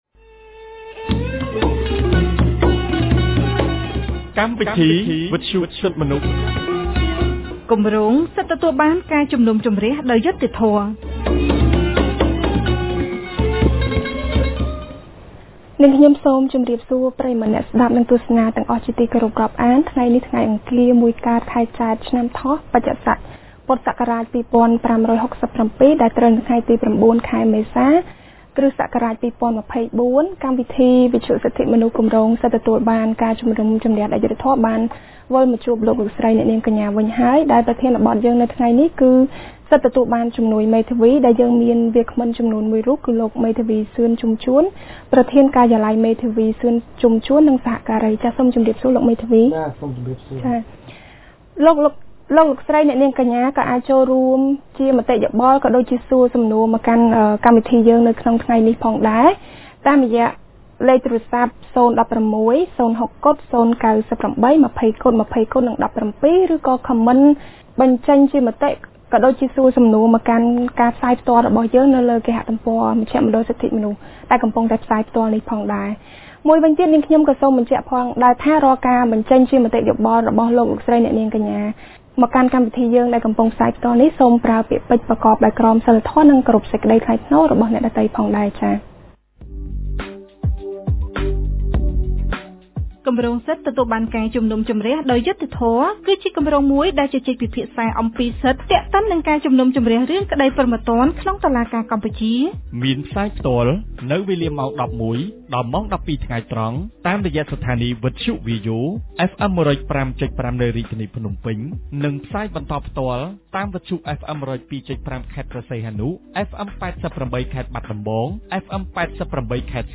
កាលពីថ្ងៃអង្គារ ទី០៩ ខែមេសា ឆ្នាំ២០២៤ ចាប់ពីវេលាម៉ោង១១:០០ ដល់ម៉ោង ១២:០០ថ្ងៃត្រង់ គម្រាងសិទ្ធិទទួលបានការជំនុំជម្រះដោយយុត្តិធម៌នៃមជ្ឈមណ្ឌលសិទ្ធិមនុស្សកម្ពុជា បានរៀបចំកម្មវិធីវិទ្យុក្រោមប្រធានបទស្តីពី “សិទ្ធិទទួលបានជំនួយមេធាវី”